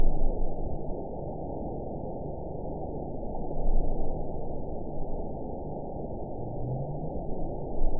event 912238 date 03/21/22 time 21:36:58 GMT (3 years, 1 month ago) score 9.10 location TSS-AB03 detected by nrw target species NRW annotations +NRW Spectrogram: Frequency (kHz) vs. Time (s) audio not available .wav